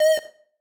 synth3_1.ogg